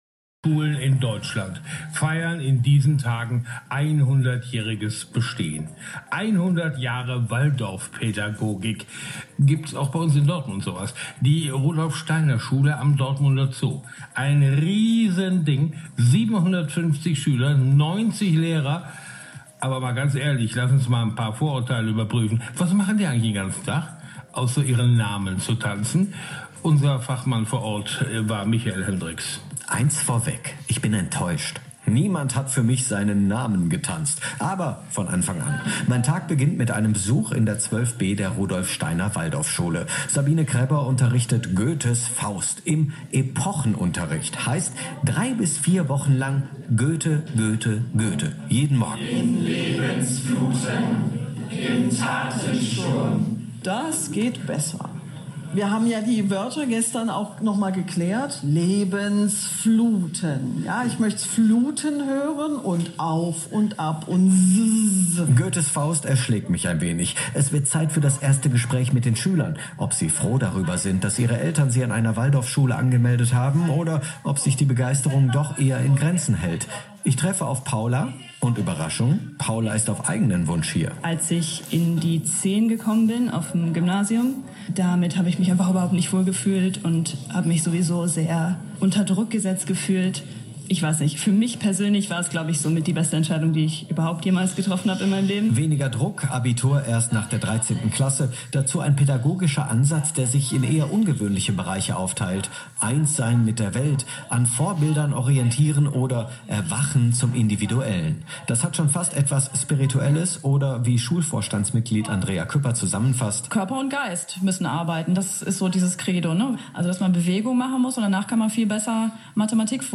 Radio 91.2 zu Gast an unserer Schule